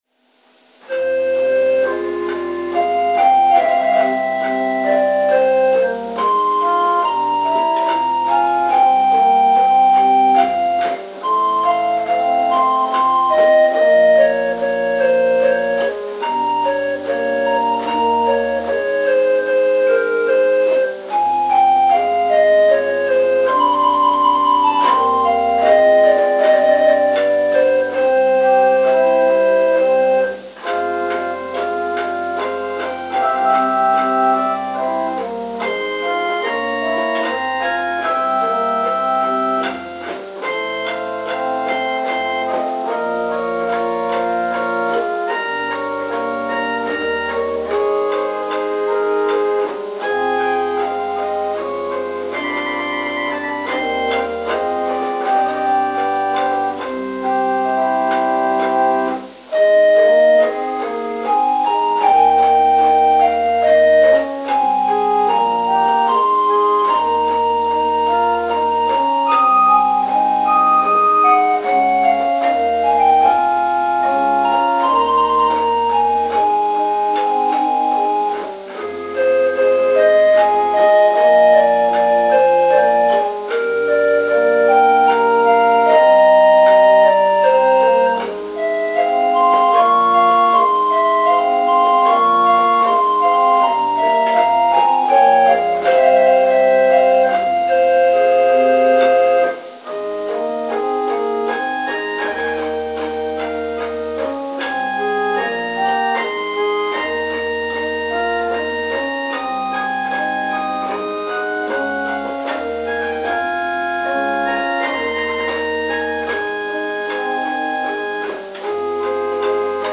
Interlude: “Air Tendre” – Jean-Baptiste Lully